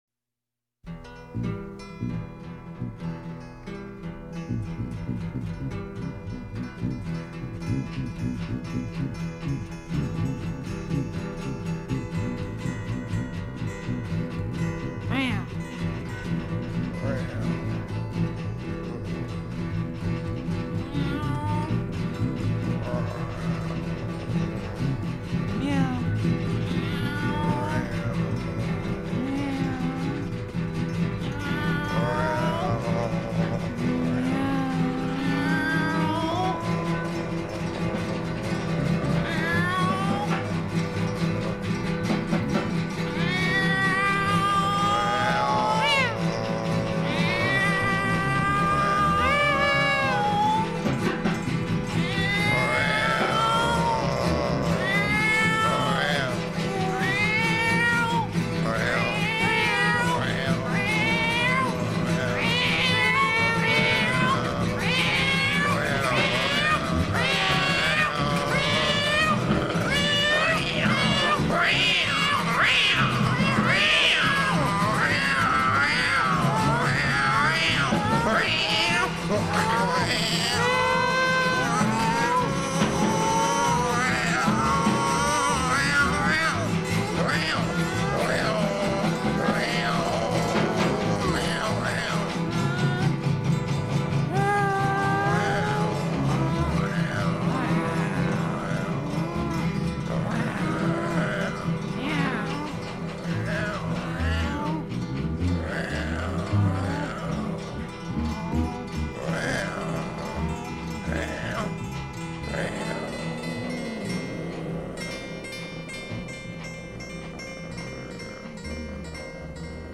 А для друзей , делюсь пением кошек и петушка - от них  волей-неволей поднимкшься.